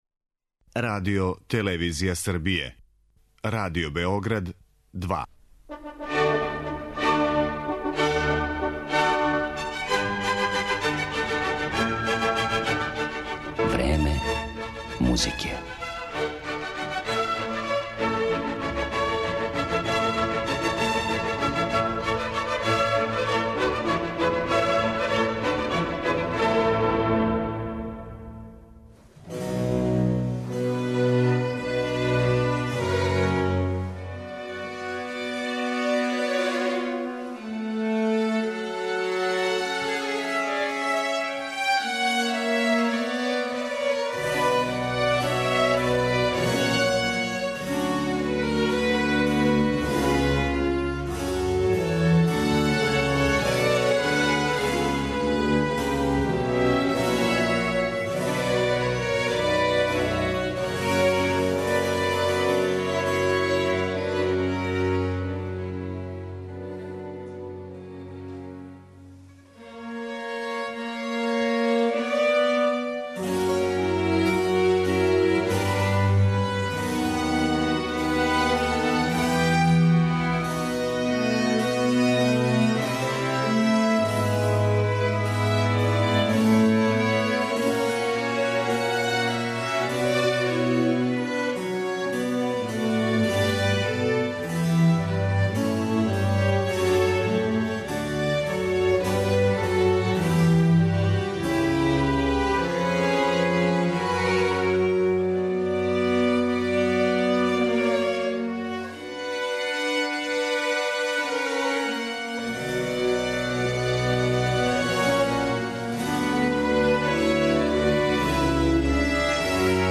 Хелсиншки барокни оркестар, чији чланови свирају на оригиналним старим инструментима, постоји од 1997. године.